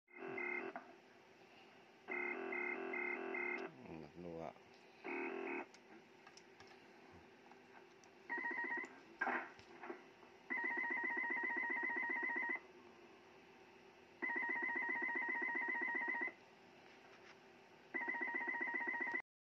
beacon untuk semua jenis radio sound effects free download